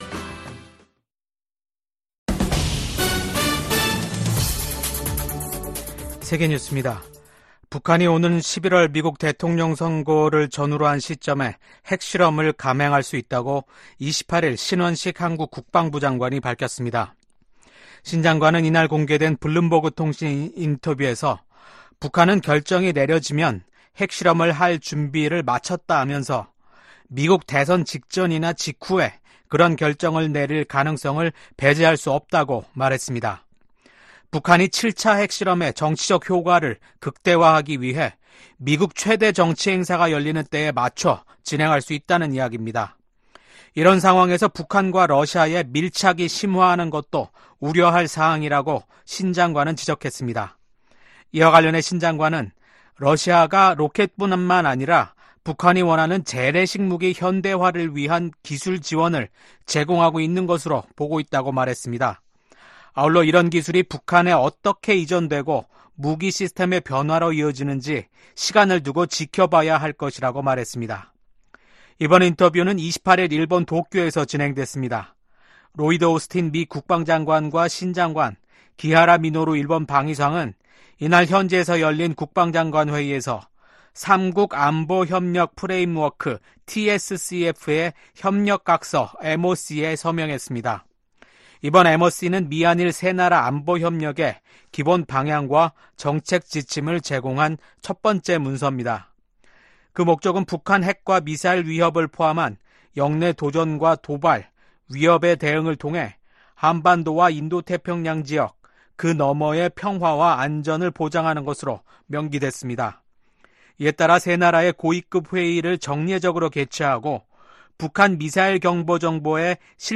VOA 한국어 아침 뉴스 프로그램 '워싱턴 뉴스 광장' 2024년 7월 30일 방송입니다. 미국과 한국, 일본이 안보 협력을 제도화하는 문서에 서명했습니다.